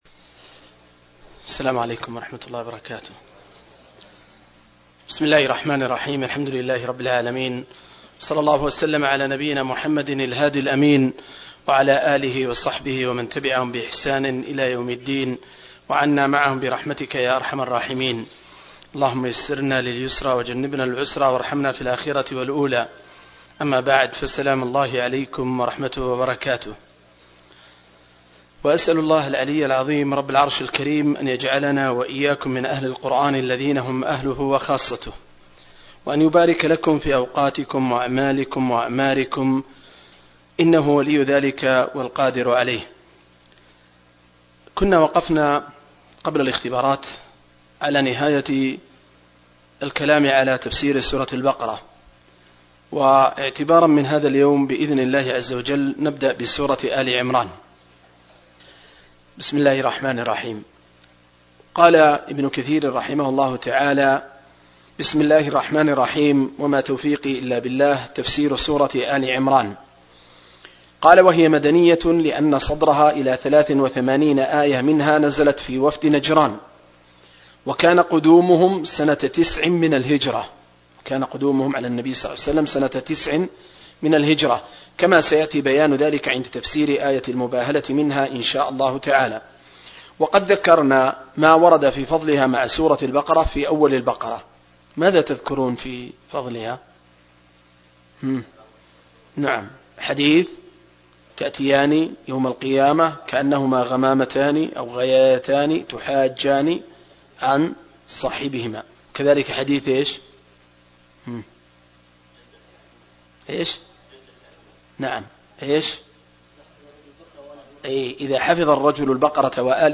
061- عمدة التفسير عن الحافظ ابن كثير رحمه الله للعلامة أحمد شاكر رحمه الله – قراءة وتعليق –